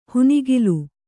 ♪ hunigiluy